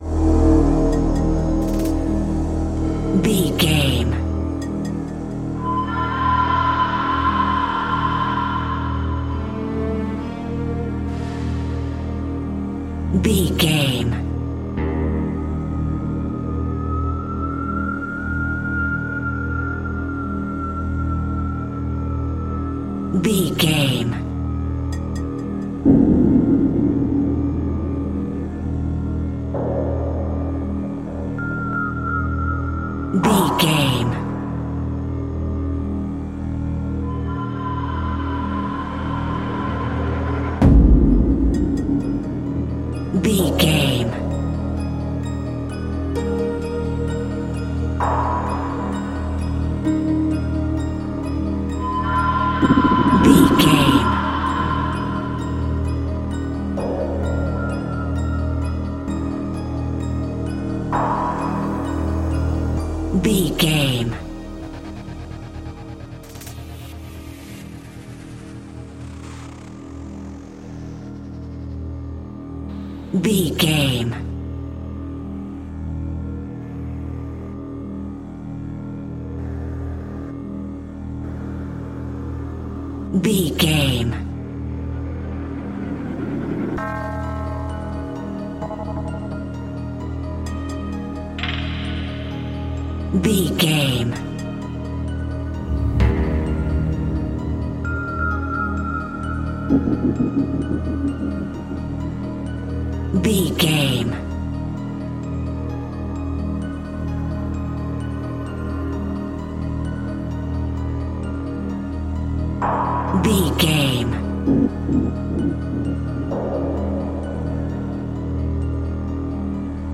Aeolian/Minor
E♭
synthesiser
percussion